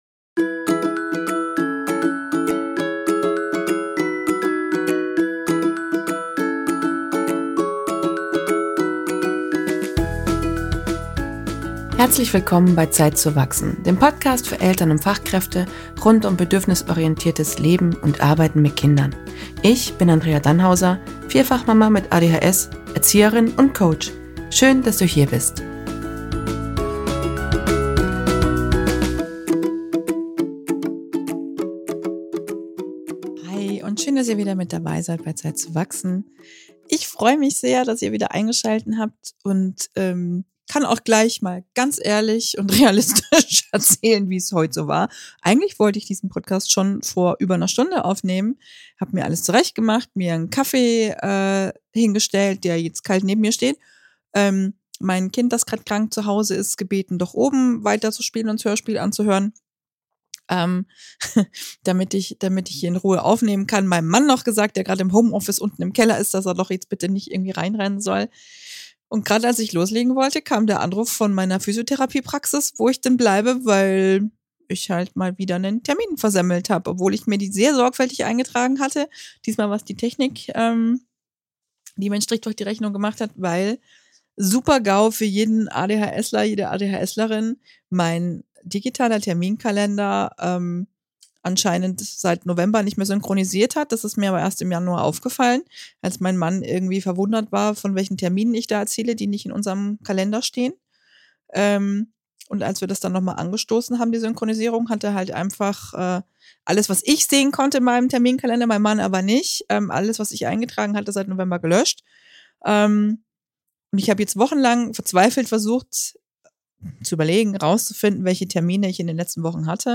Eine ehrliche Solofolge über das Bedürfnis nach Ordnung gepaart mit der Tendenz überall Chaos zu hinterlassen.